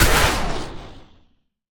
sounds / mob / breeze / death1.ogg
death1.ogg